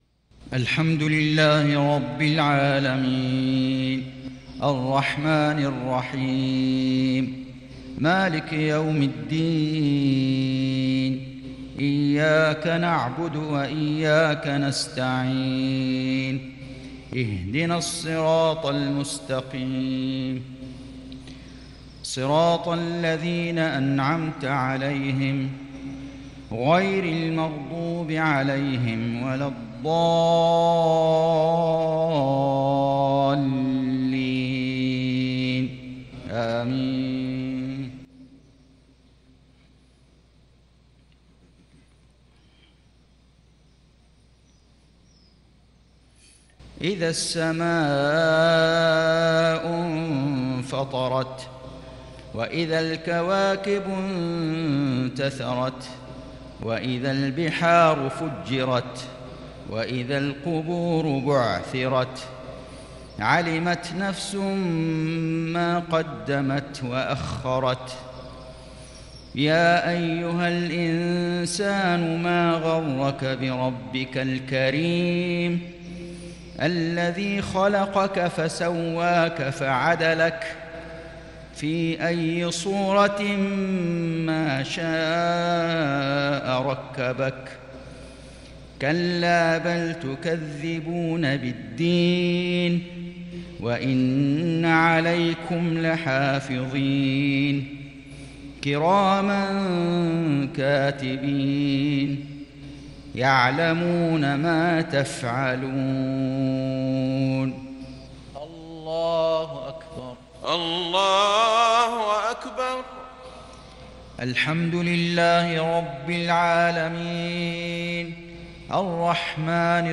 مغرب الجمعة 1-2-1442 هـ سورة الانفطار | Maghrib prayer Surah Al-Infitaar 18/9/2020 > 1442 🕋 > الفروض - تلاوات الحرمين